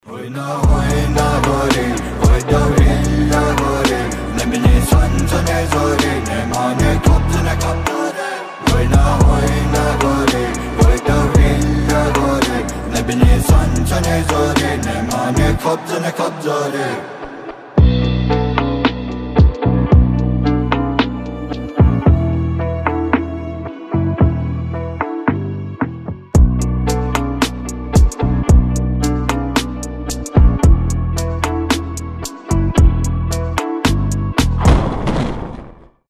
• Качество: 320, Stereo
Хип-хоп
грустные